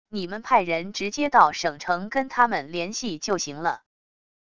你们派人直接到省城跟他们联系就行了wav音频生成系统WAV Audio Player